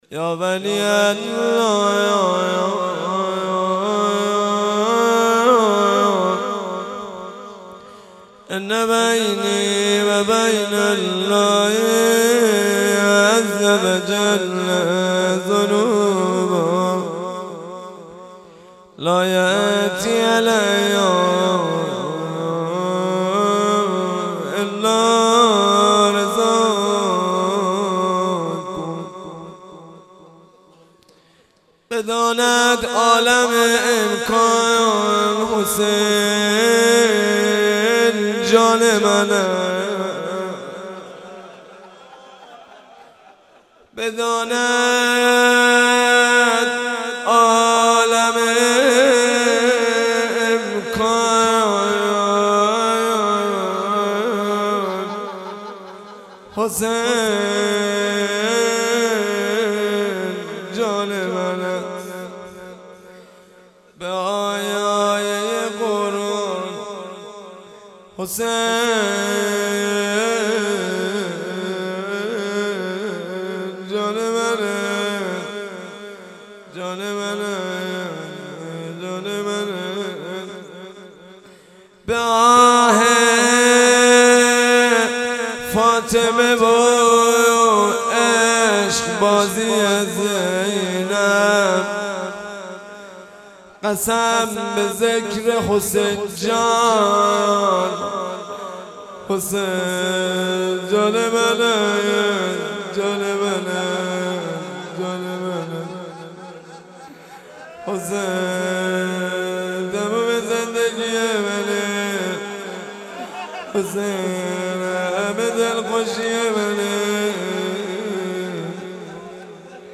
روضه شب عاشورا